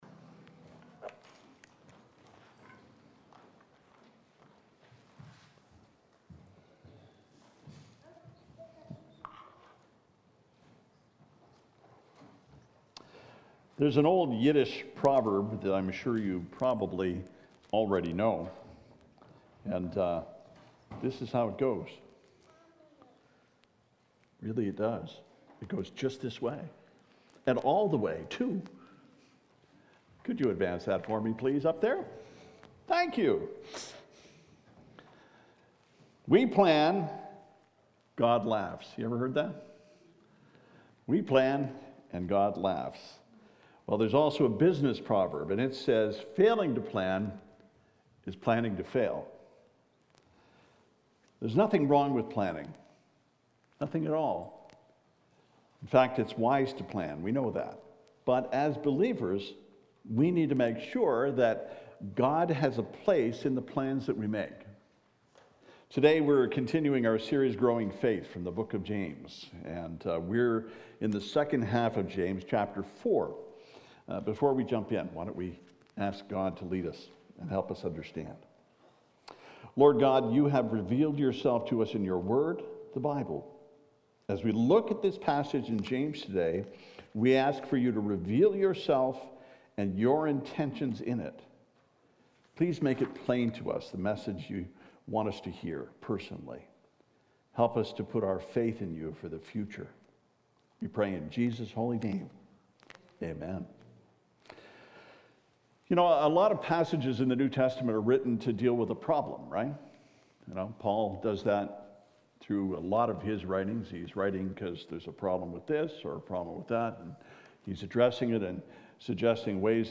“Planning To Fail” James 4:13-17 « FABIC Sermons